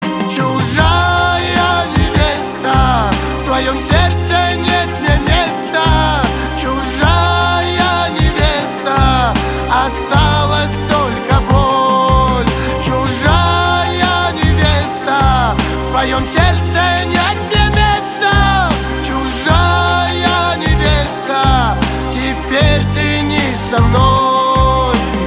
• Качество: 161, Stereo
грустные
русский шансон
кавказские